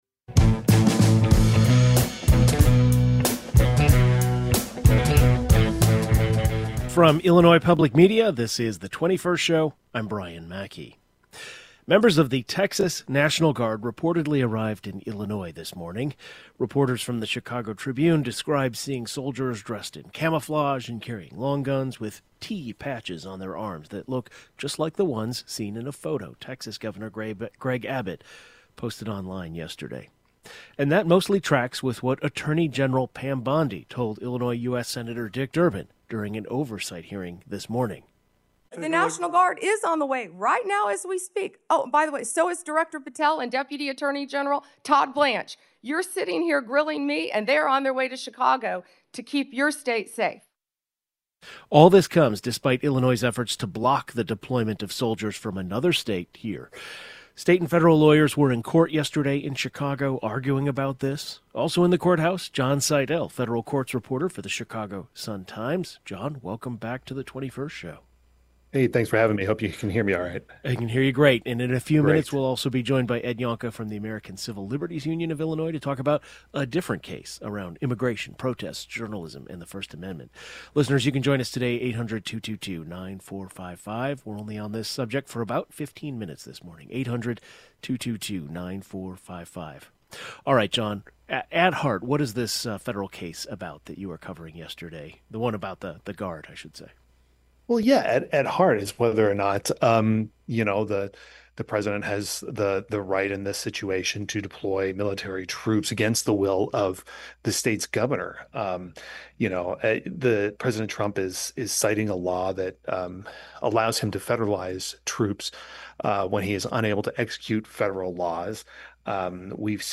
We talked with people on the ground after President Trump deployed National Guard troops to Illinois.